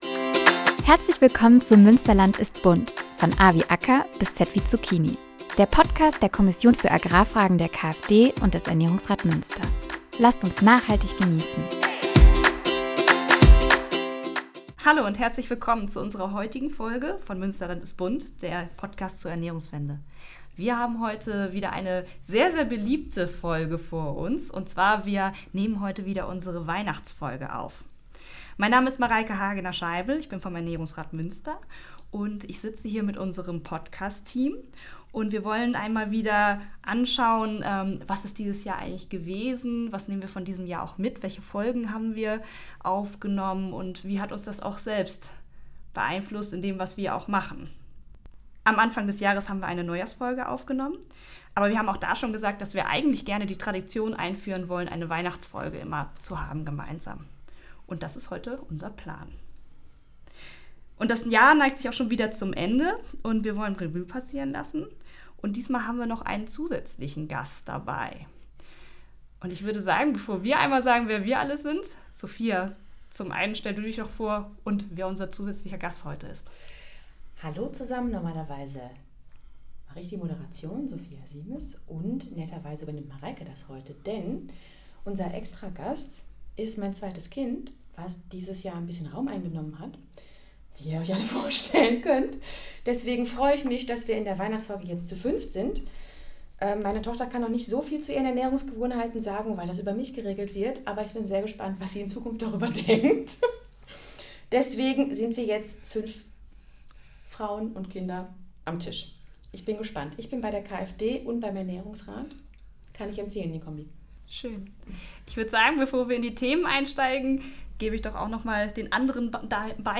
In unserer diesjährigen Weihnachtsfolge setzen wir uns als Podcastteam gemeinsam zusammen und lassen das Jahr 2025 Revue passieren.